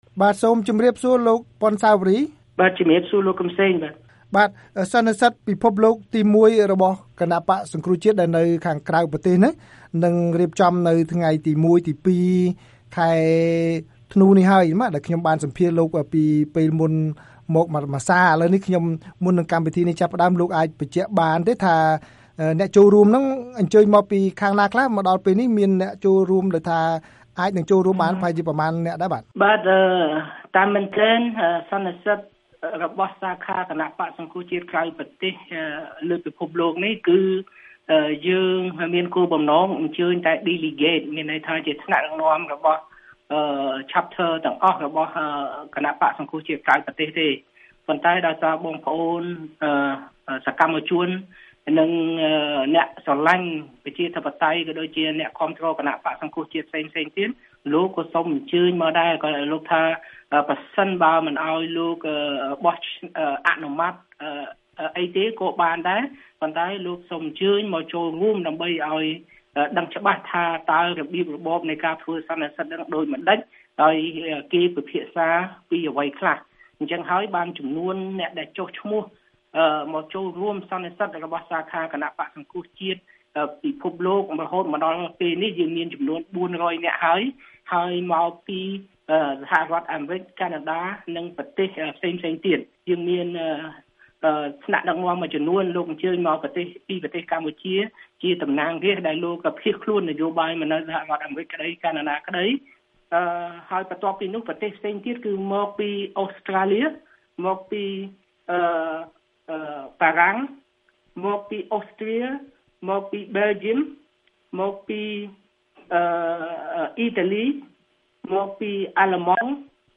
សម្ភាសន៍VOA៖ អ្នកគាំទ្រសង្គ្រោះជាតិនៅក្រៅប្រទេសនឹងរៀបចំសន្និសីទលើកទី១